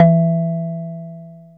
JUP.8 E3   3.wav